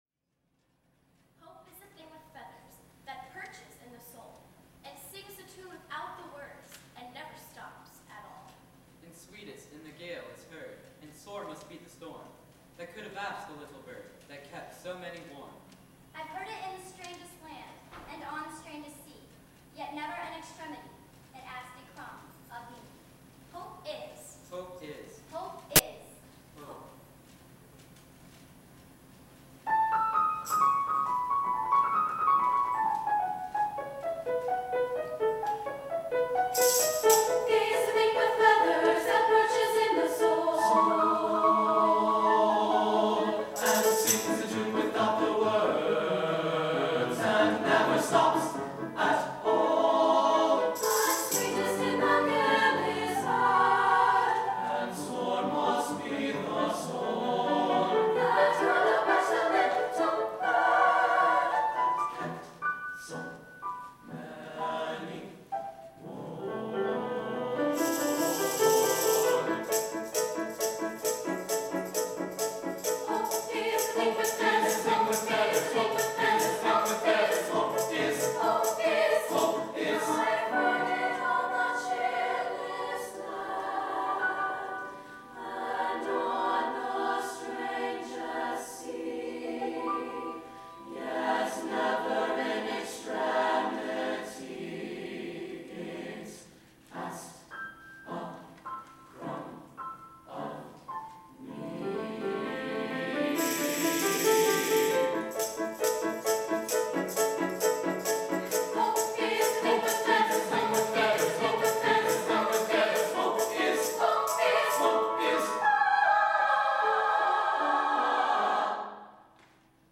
for SATB Chorus, Piano, and Opt. Percussion (2006)